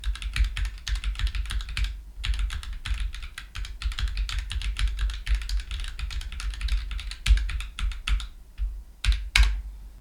打鍵音は大きめのキーボードです。
1：ノイズゲートOFF状態
2：キーボードからマイクまで約30cm